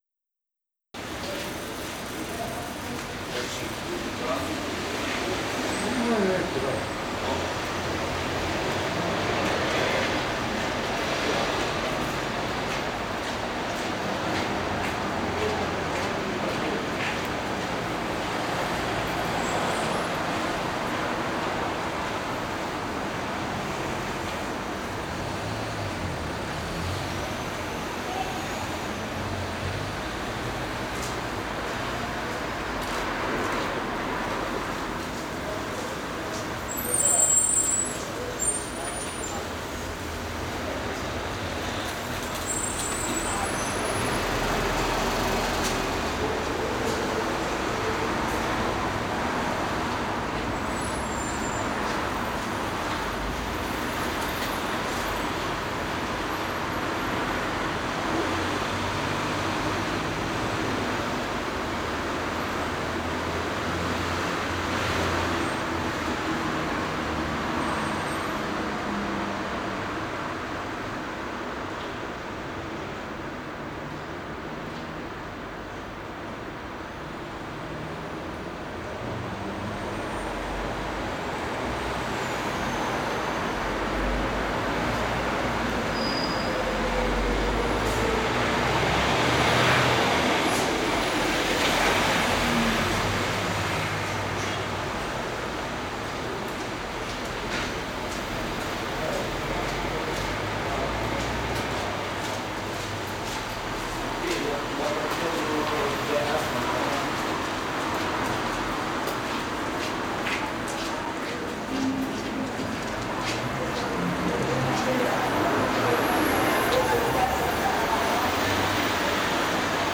2020-12-30 Mond aufm Oberbaum (Original Video Ton).flac
(Fieldrecording Video) Video: Digitale Kompakt Kamera Olympus XZ-1 (HD)